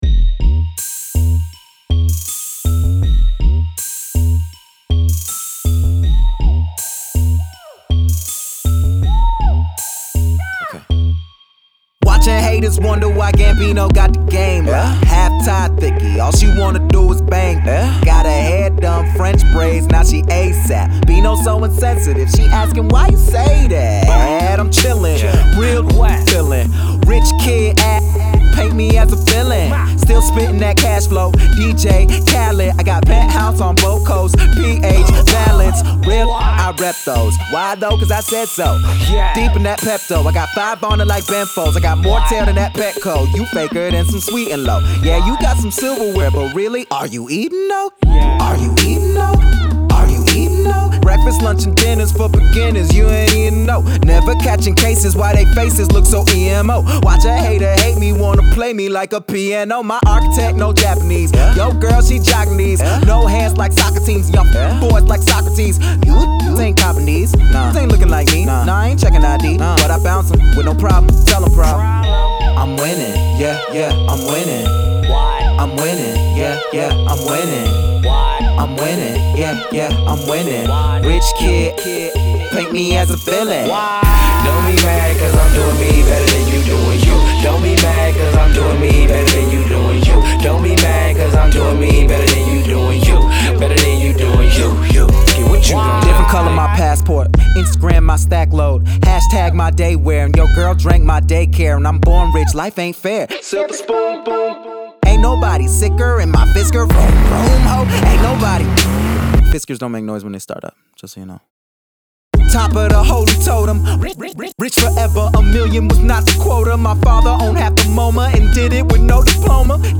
swag rap mode flipping rapid-fire punchlines